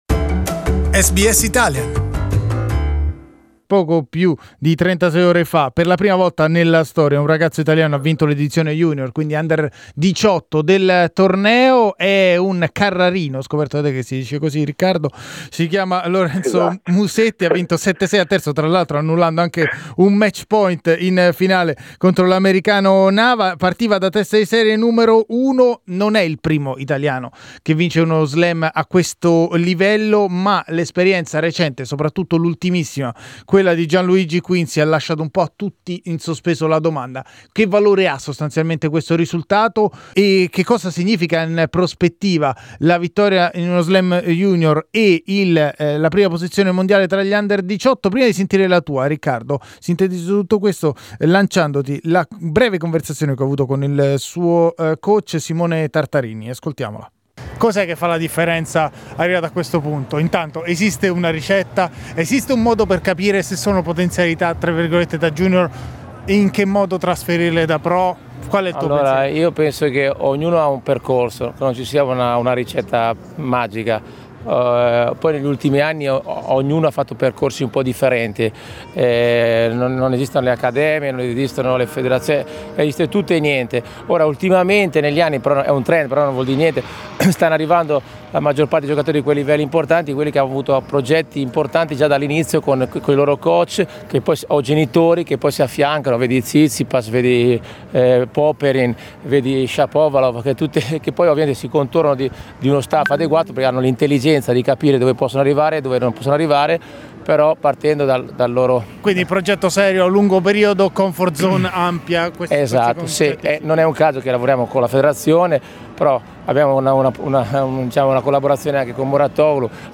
Il podcast include anche l'intervista esclusiva registrata con Lorenzo Musetti subito dopo il trionfo.